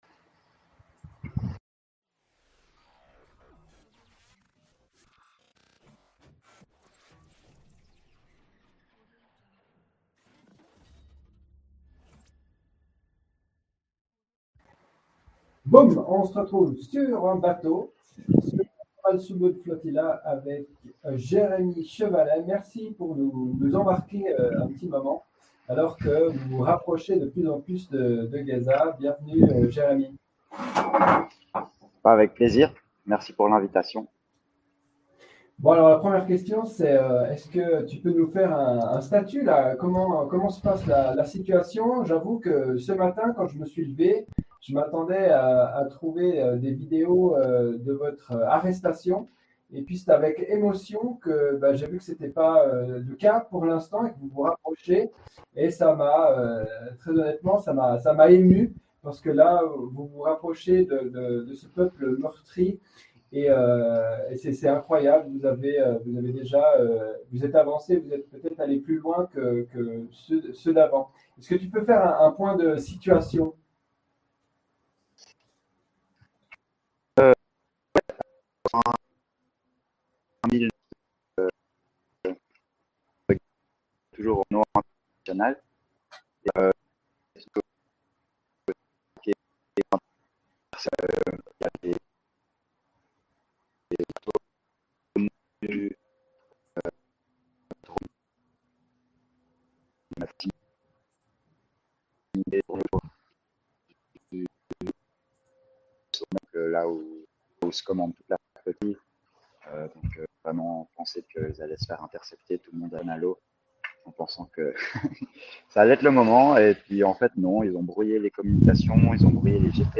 Excusez-nous pour la qualité du son.